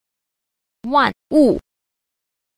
2. 萬事 萬物 – wànshì wànwù – vạn sự vạn vật
Cách đọc: